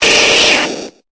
Cri de Carabaffe dans Pokémon Épée et Bouclier.